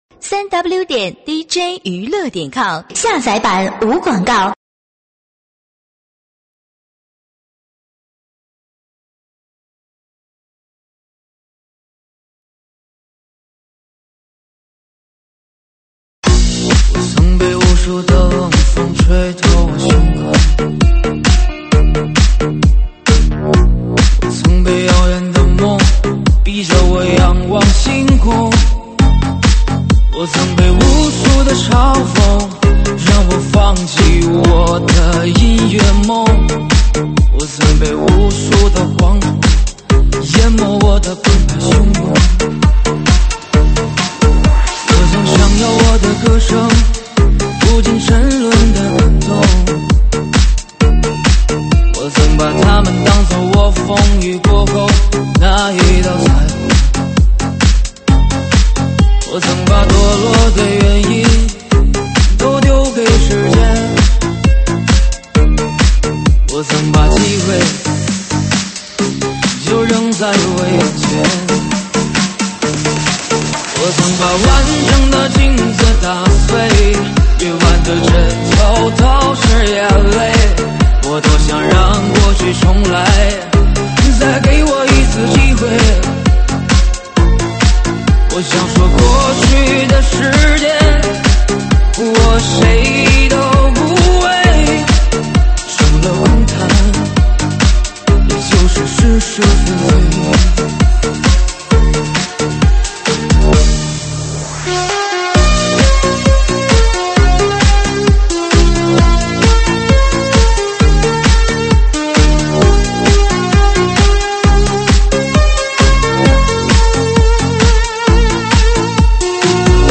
车载大碟